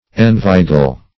Meaning of enveigle. enveigle synonyms, pronunciation, spelling and more from Free Dictionary.
enveigle - definition of enveigle - synonyms, pronunciation, spelling from Free Dictionary Search Result for " enveigle" : The Collaborative International Dictionary of English v.0.48: Enveigle \En*vei"gle\, v. t. To entice.